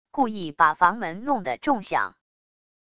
故意把房门弄的重响wav音频